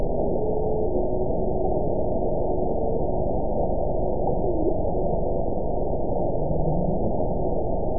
event 922437 date 01/01/25 time 02:32:35 GMT (11 months ago) score 9.28 location TSS-AB02 detected by nrw target species NRW annotations +NRW Spectrogram: Frequency (kHz) vs. Time (s) audio not available .wav